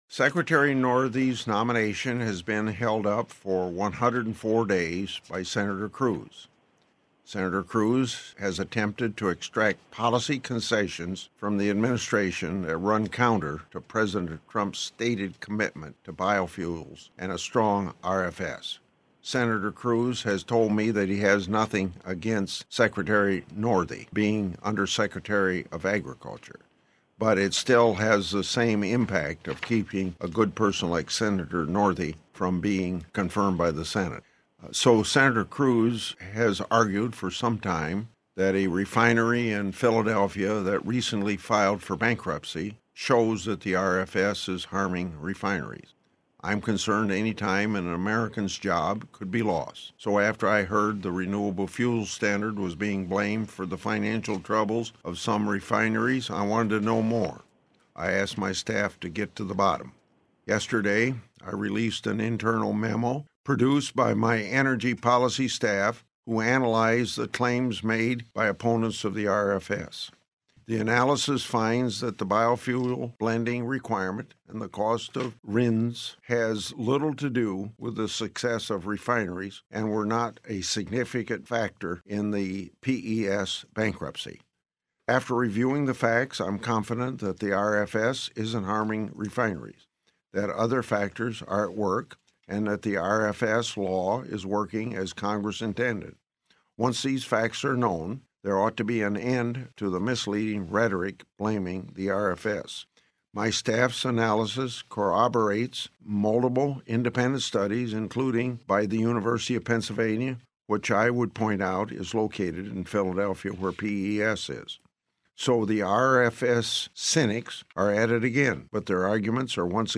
Grassley on Conference Call with Iowa Reporters